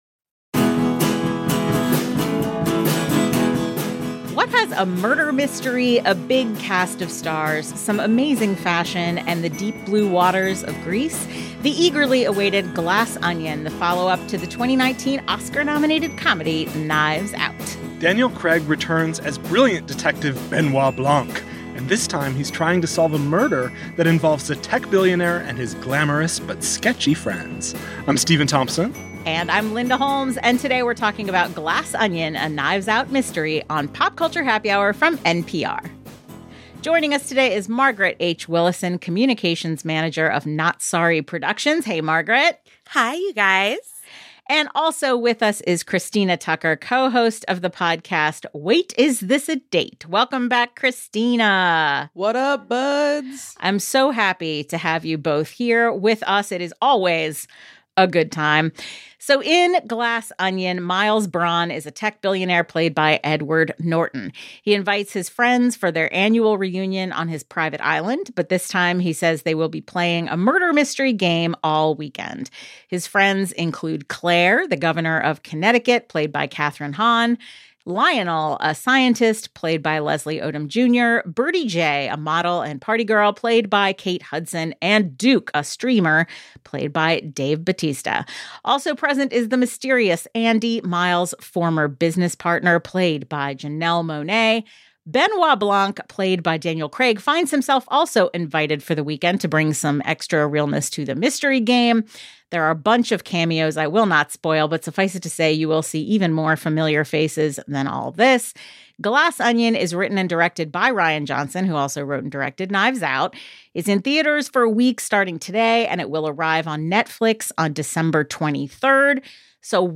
Movie Review